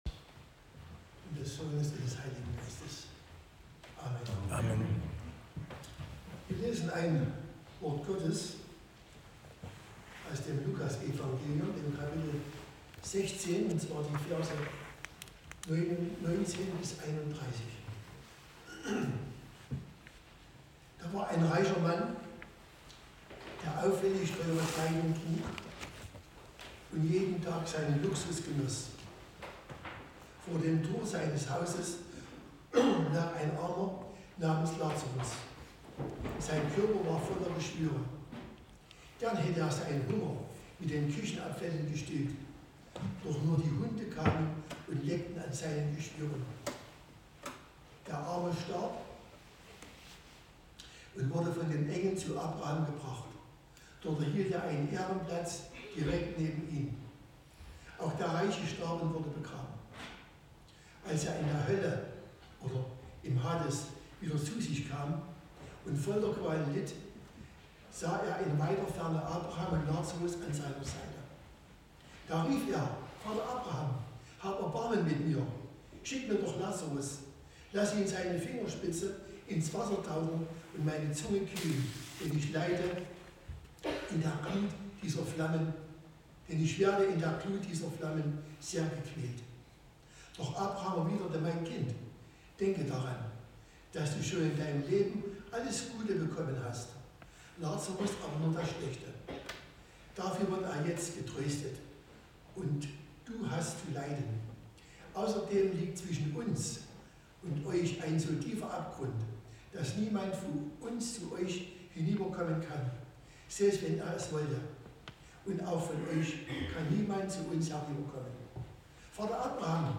Lukas 16;19-31 Gottesdienstart: Predigtgottesdienst Wildenau Wer von Menschen vergessen wird